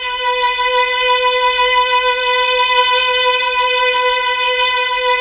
Index of /90_sSampleCDs/Roland LCDP09 Keys of the 60s and 70s 1/KEY_Chamberlin/STR_Cham Slo Str